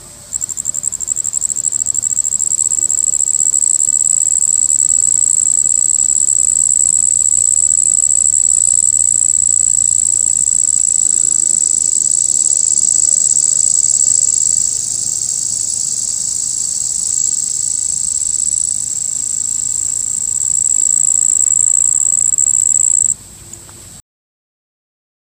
Bush by woodpile in Lime Kiln Farms, Churchville
Red headed bush cricket calling from bush.